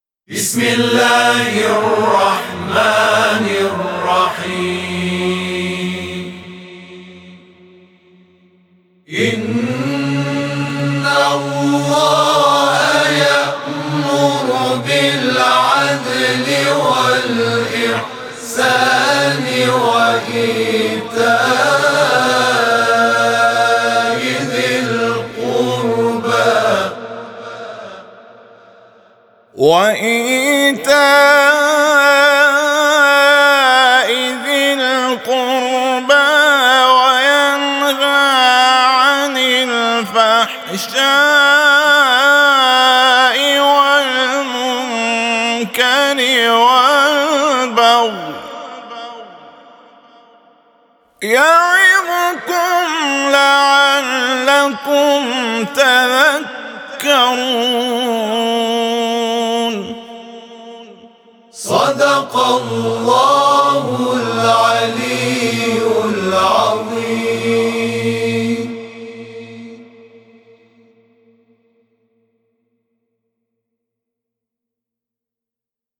صوت همخوانی آیه 90 سوره نحل از سوی گروه تواشیح «محمد رسول‌الله(ص)»